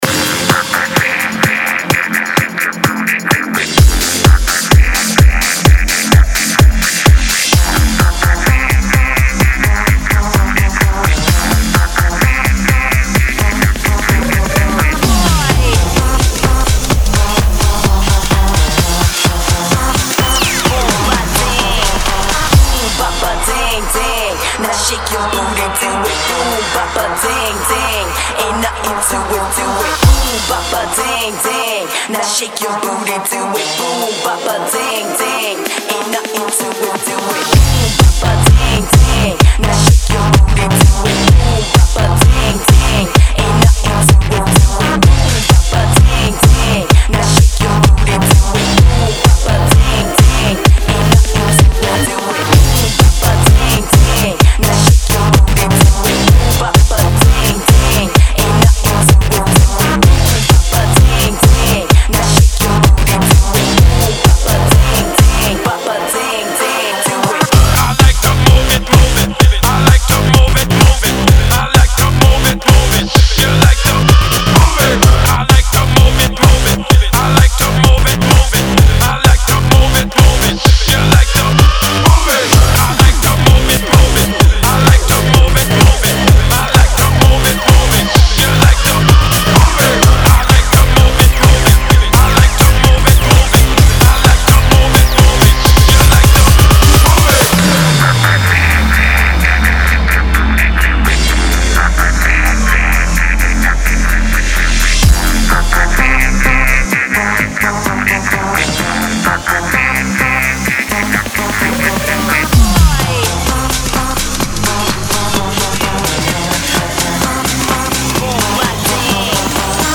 Категория: Клубняк | Добавил: Admin